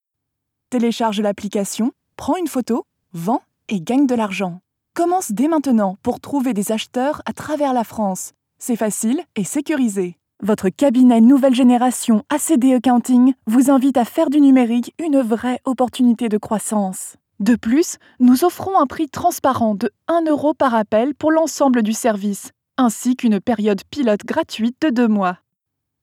Sprechprobe: Werbung (Muttersprache):
I am a French (Paris) and US-English voice actress.